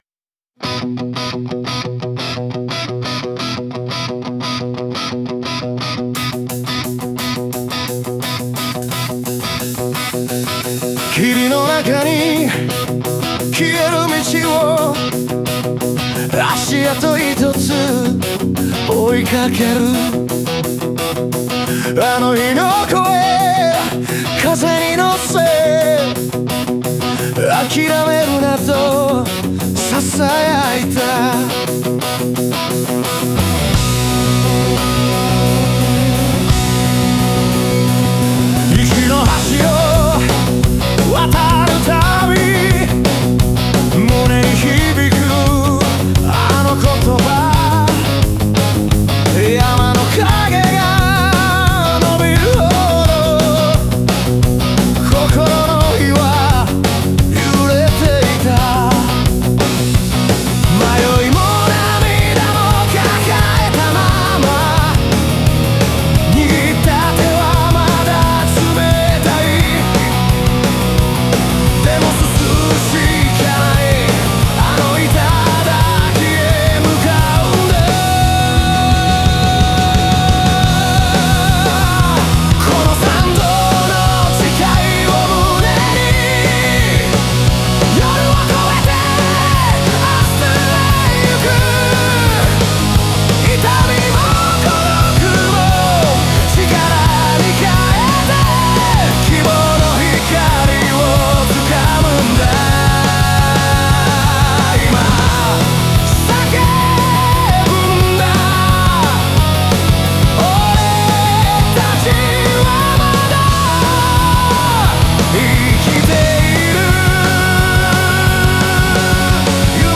全体を通して、試練を乗り越える人間の強さと前向きな精神を、ロック調のダイナミックな音楽と重ね合わせて表現している。